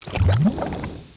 c_slime_bat1.wav